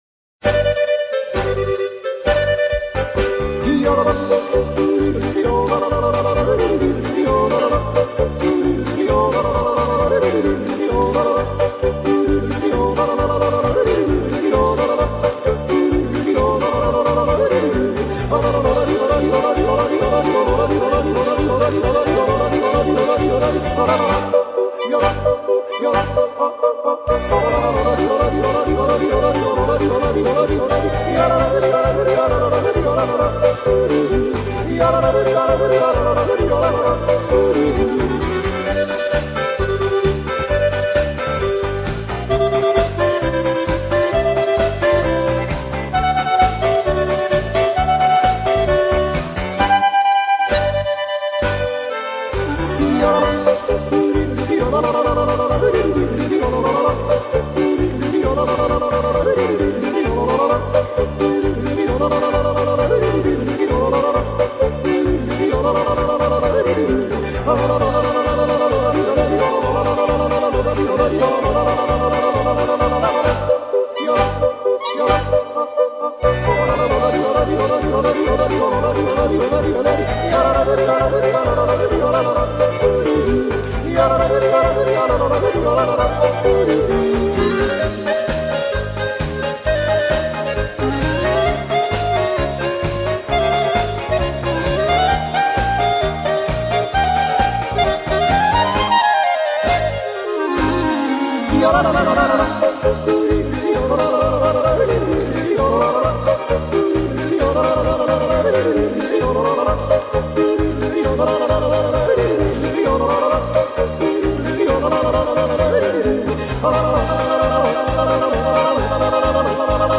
ACORDEON - ÓRGÃO
Folclore Austríaco/Tirolês